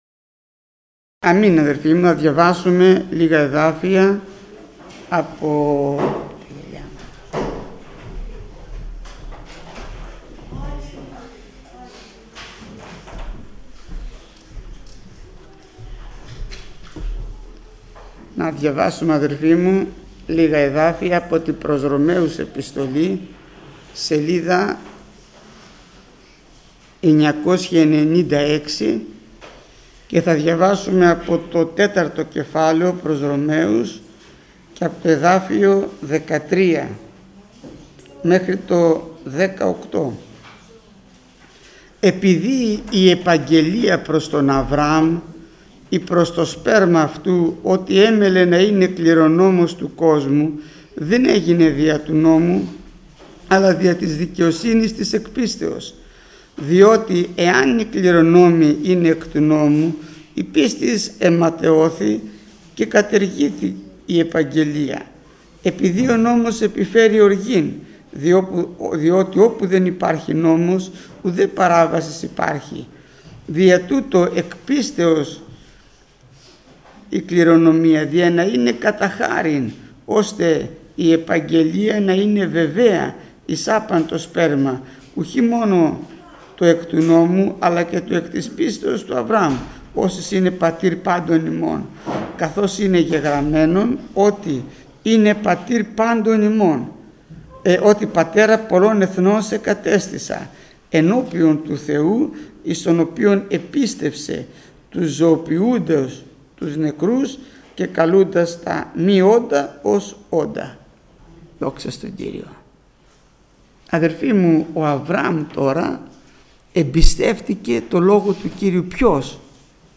Μήνυμα πριν τη θεία κοινωνία
Μηνύματα Θείας Κοινωνίας